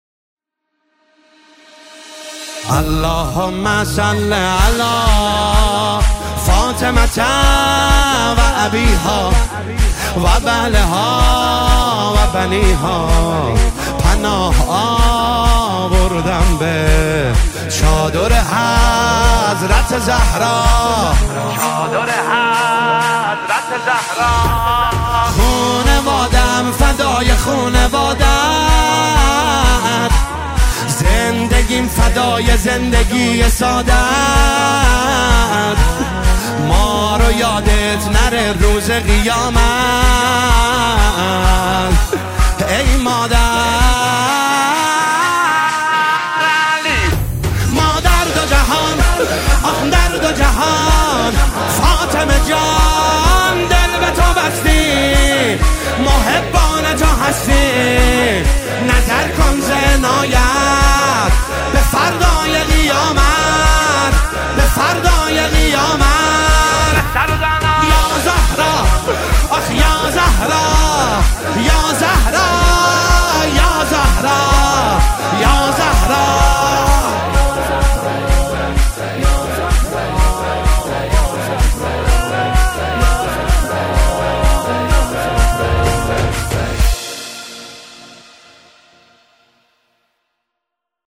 نماهنگ دلنشین
مداحی مذهبی